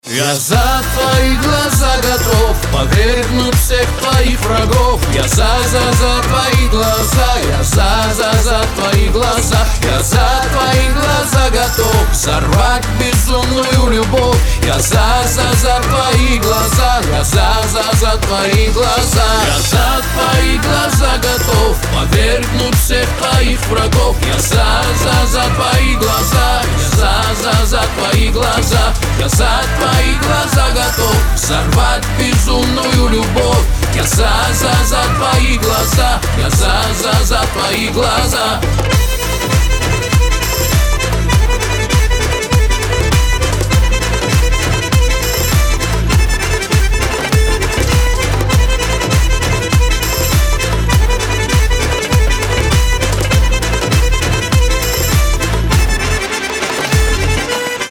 • Качество: 320, Stereo
поп
dance
кавказские